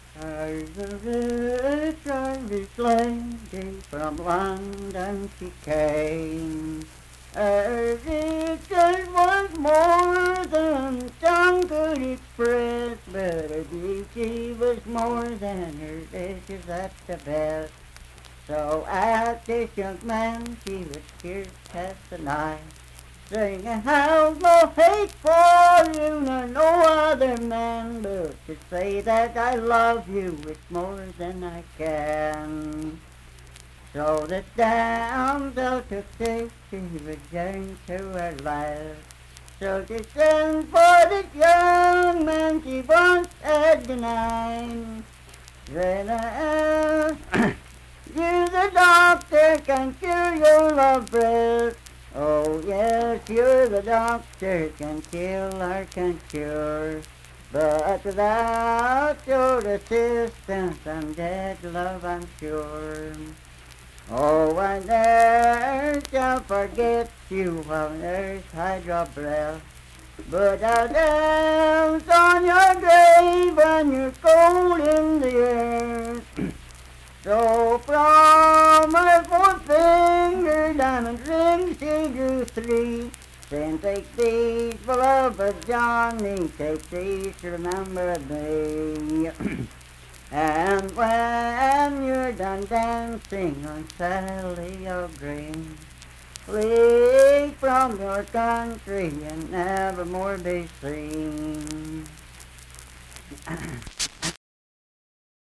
Unaccompanied vocal music performance
Verse-refrain 5(2-5).
Voice (sung)